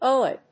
読み方 オーエーユー